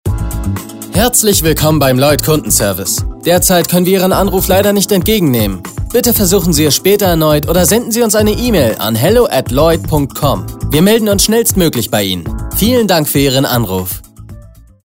LLOYD wünschte sich eine frische, dynamische und unverbrauchte Stimme.
Ansage Llyod Kundenservice
LLoyd-3a-Kundenservice.mp3